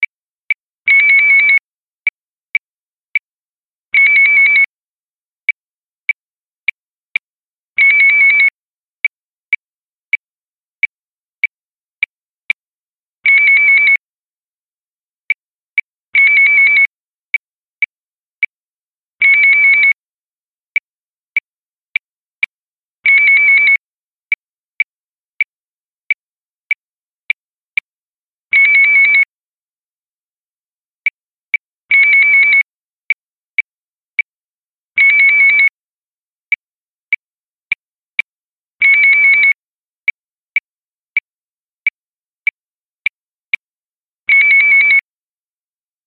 Som Urna Eletronica Barulho Toque Efeito Sonoro Baixar Mp3 Áudio de Aviso
Vinheta barulho toque áudio aviso de efeito sonoro quando termina de votar e confirma a votação, download grátis.